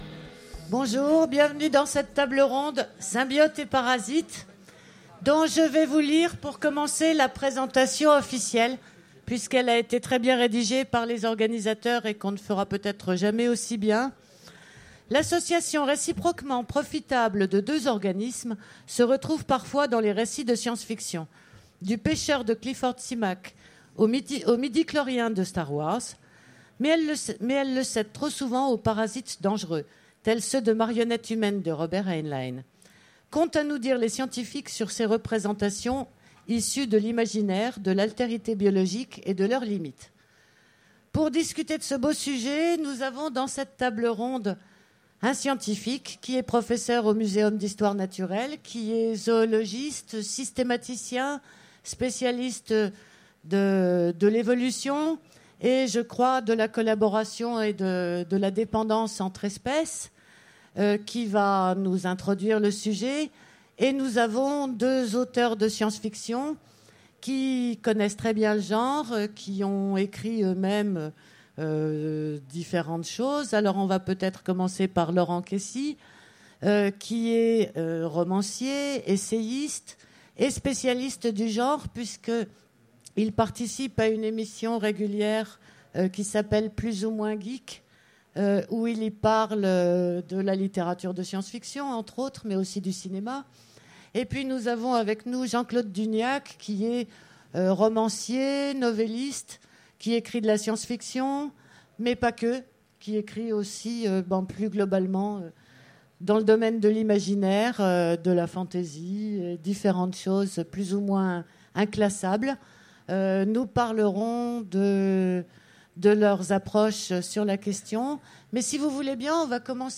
Utopiales 2015 : Conférence Symbiotes et parasites